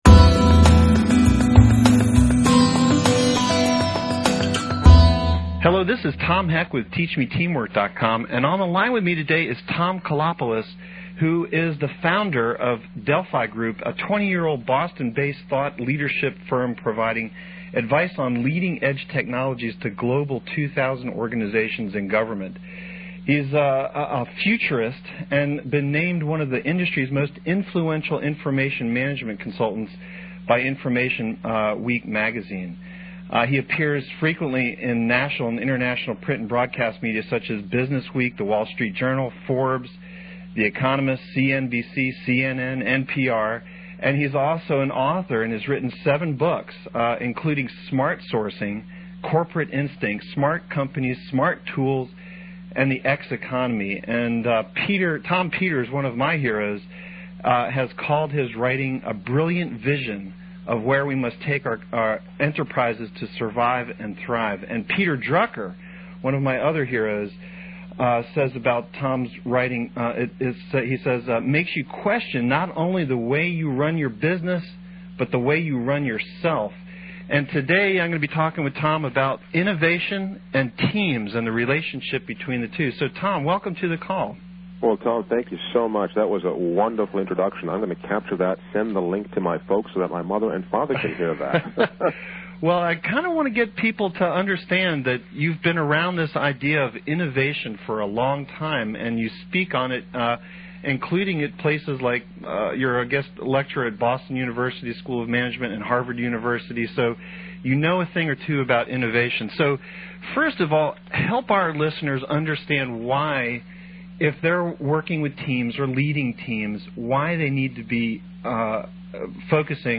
Innovation and Teamwork — an Audio Interview with innovation expert